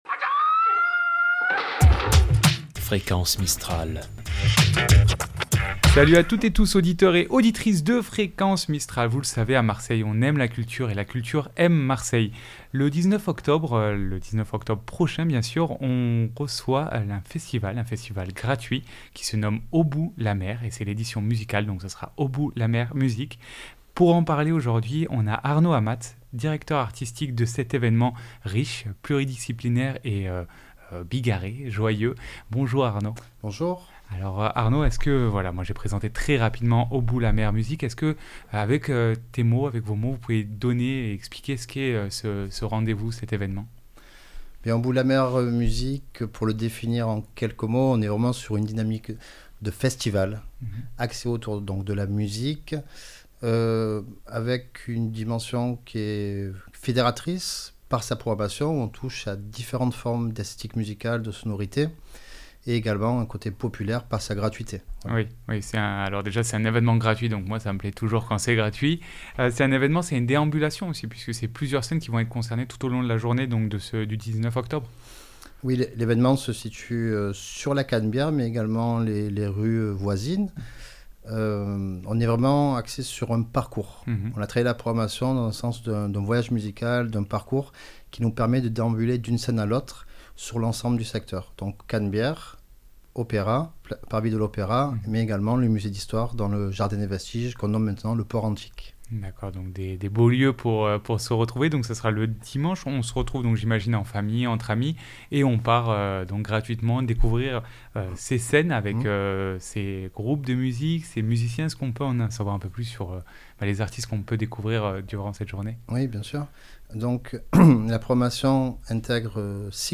ITW Au bout la mer .mp3 (16.56 Mo)